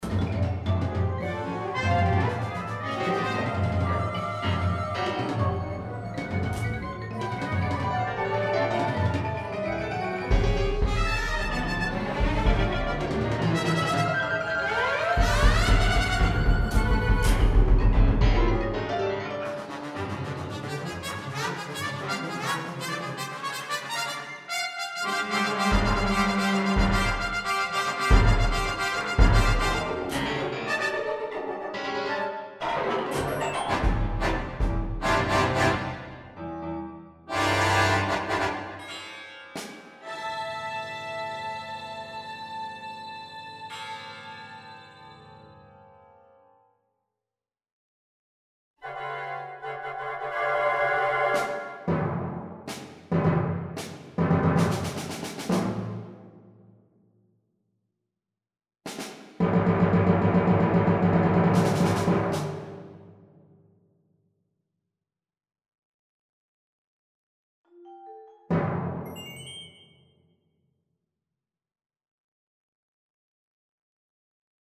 for large orchestra
Percussion (three players)
Piano, celesta, harp